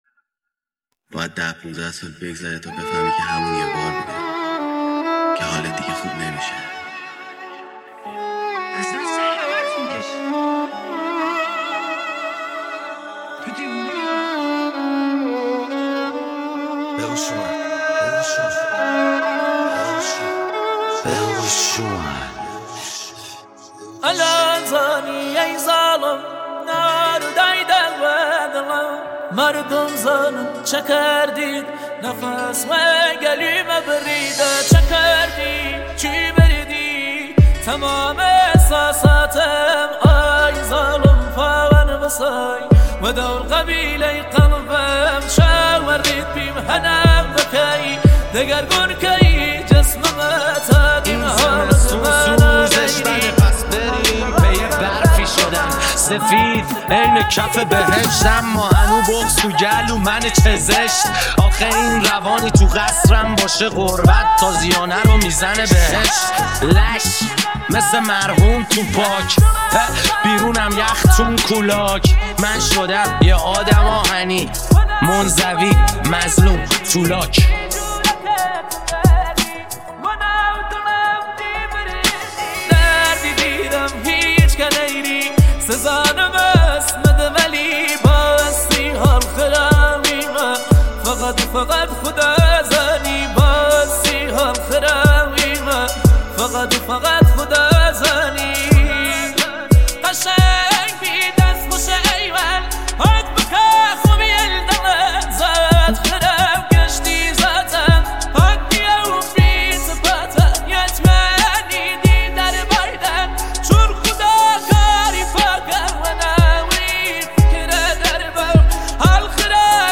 ریمیکس ترکیبی رپ